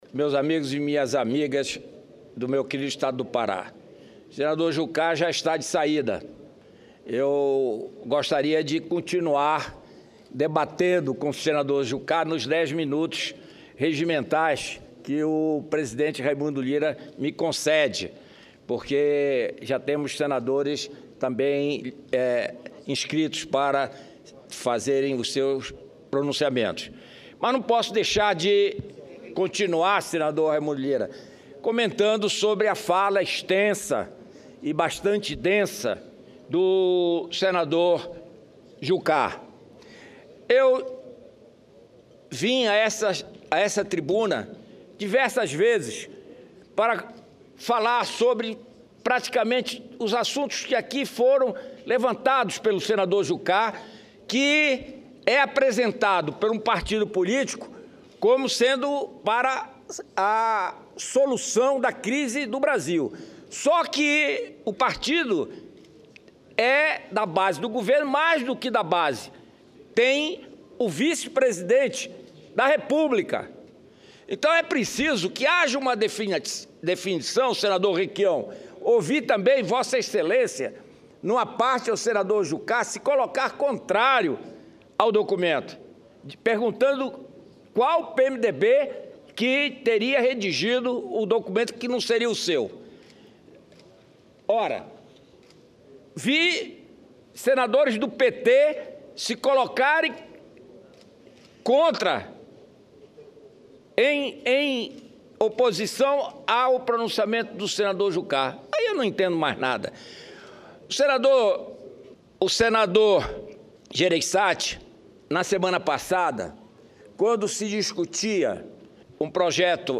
Discusos